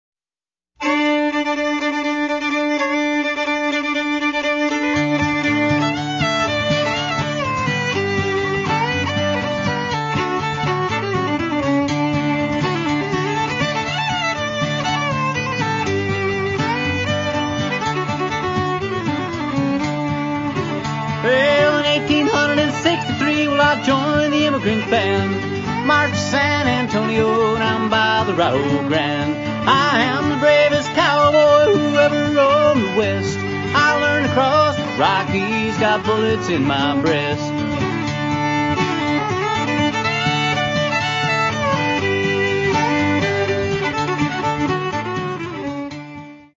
Old-Time Songs & Longbow Fiddle
guitar & lead vocals
fiddle